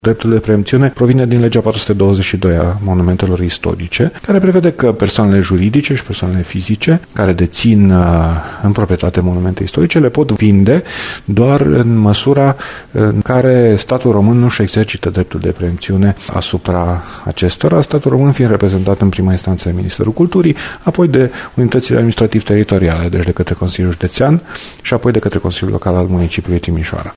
Dan Diaconu a explicat și motivul pentru care proprietarii sunt obligați să întrebe primăria dacă vrea să cumpere clădirea înainte de a o vinde altora: